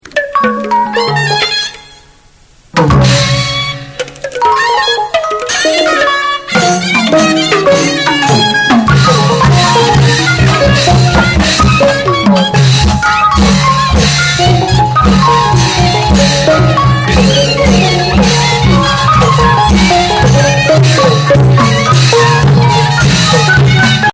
The mysterious and flamboyant Burmese drum and gong ensemble, rarely heard outside Burma (and is increasingly rare within Burma as well).
Although these instruments are somewhat reminiscent of some traditional Thai or Indonesian gong ensembles, this seems almost reckless, sometimes on the verge of falling apart, but suddenly coming together, only to fall into chaos yet again.
Note:  This music is from a cassette we purchased after watching the marionette show.
As it's tied to the action onstage, the music never stays at the same tempo for long.